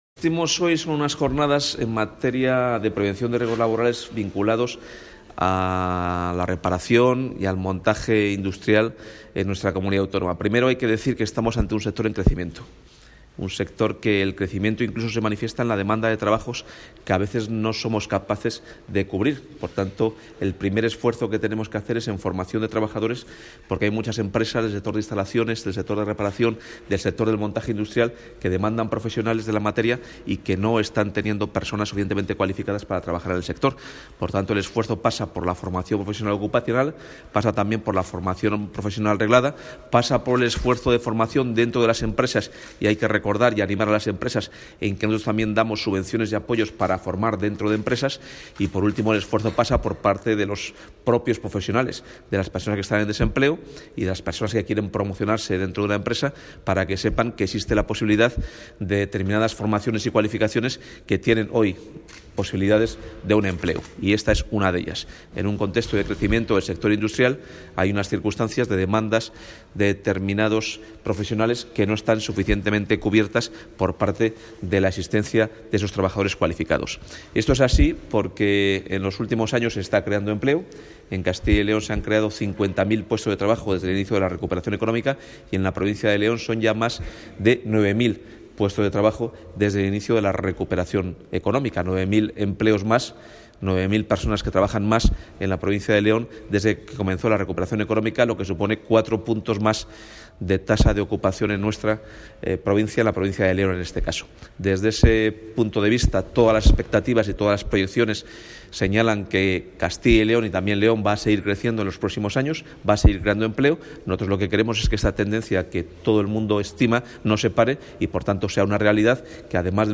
Declaraciones del consejero de Empleo.
El consejero de Empleo, Carlos Fernández Carriedo, ha intervenido en la jornada técnica sobre 'Prevención de Riesgos Laborales en operaciones de mantenimiento, montaje y reparación de instalaciones y equipos de trabajo', organizada por el centro de Seguridad y Salud Laboral de Castilla y León. La Consejería de Empleo ha destinado, desde la entrada en vigor del V Plan de Prevención de Riesgos Laborales 2016-2020, más de 11 millones de euros a la totalidad de las acciones destinadas a la Seguridad y Salud Laboral en la Comunidad.